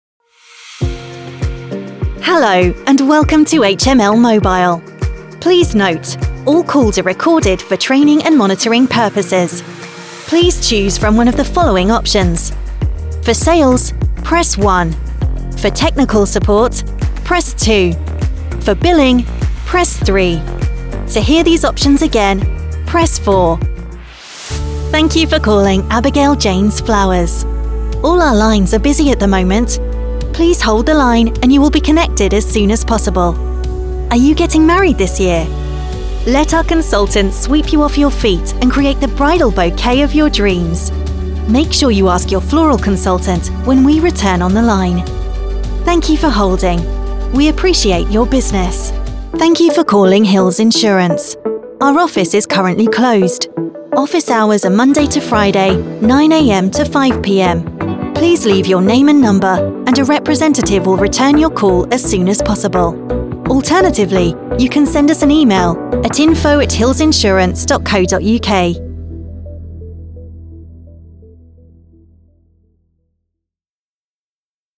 Female
Neutral British (native), RP, London, Northern British, Essex/Cockney, General American, Southern US, Australian, French, Irish, Liverpudlian, Eastern European
I can also perform multiple character voices for animation and gaming in different accents.
Character Voices:Animation Showreel.mp3
Microphone: Rode NT1-A
Audio equipment: Audient iD4 interface, treated studio booth